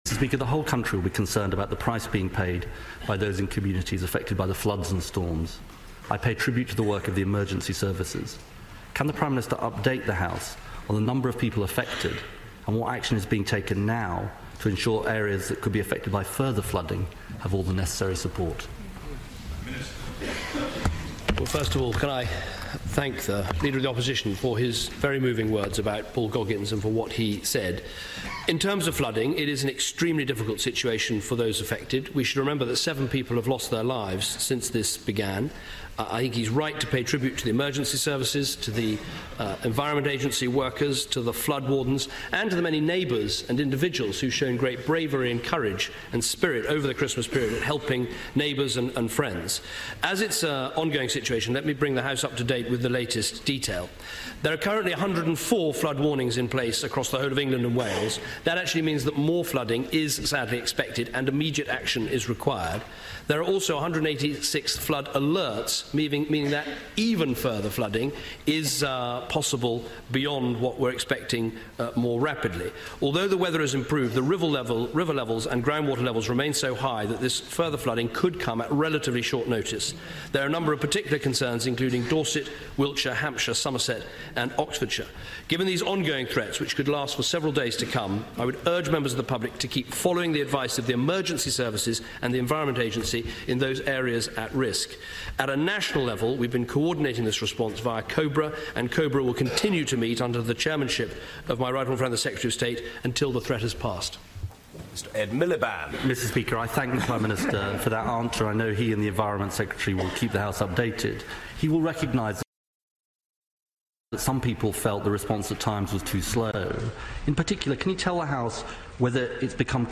PMQs, 8 January 2014